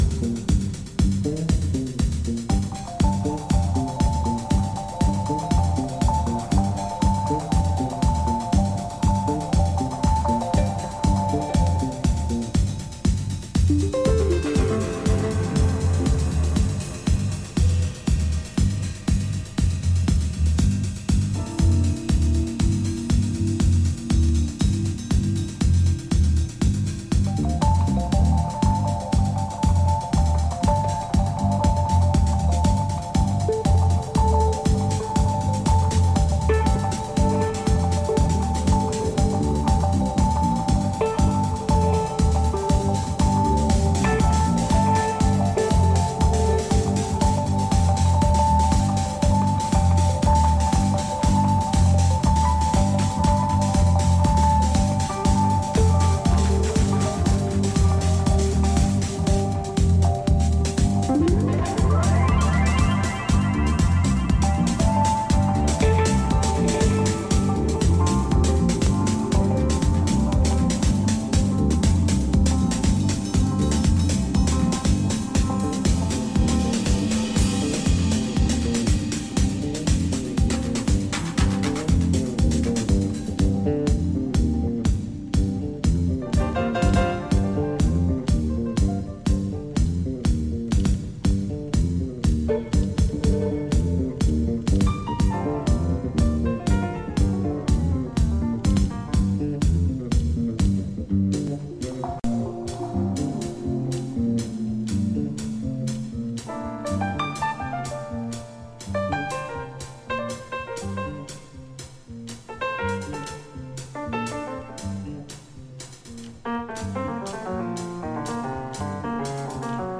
electronics
piano, Fender Rhodes, synthesizer
electric/upright bass.
Techno and electronic dance music